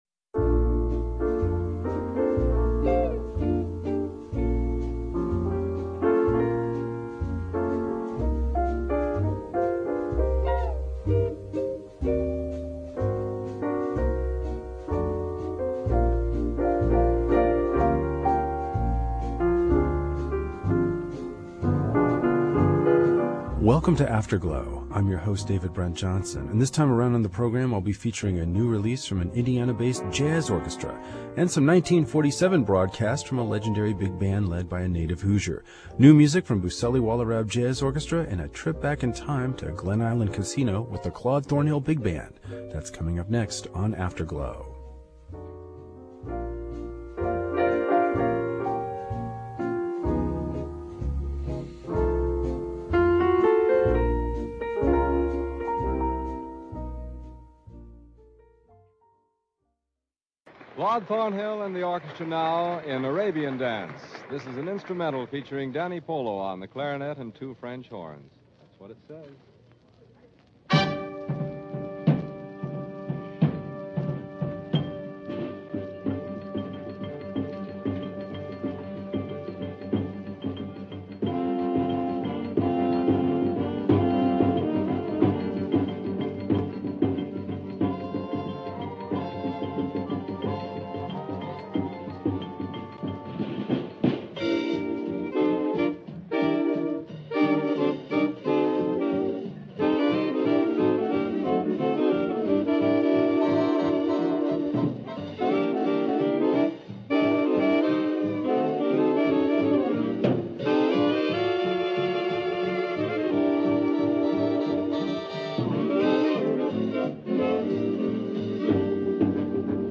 Listen in Popup Download MP3 Comment Two big bands with Indiana connections are featured this week on Afterglow --one in a historical broadcast venue, the other in a modern studio.
We'll hear some live music from the post-war Claude Thornhill big band, broadcast from the Glen Island Casino.